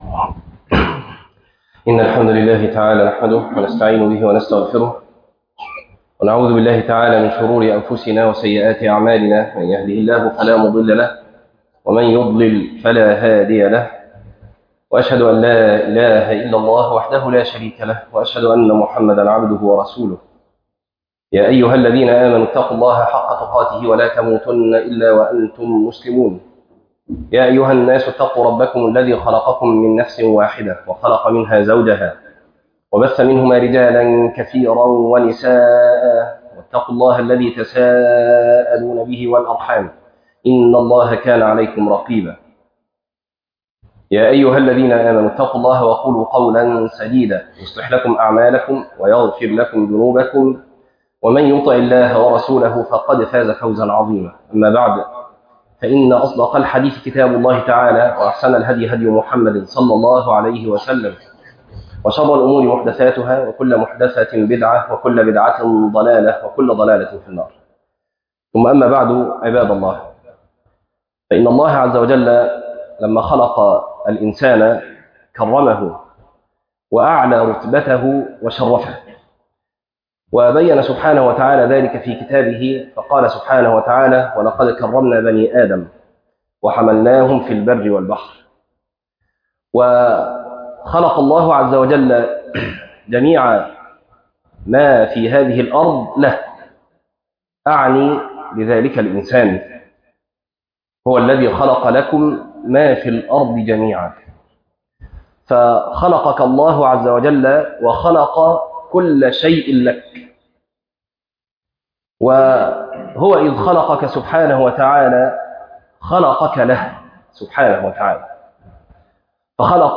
إن أكرمكم عند ٱلله أتقىكم - خطبة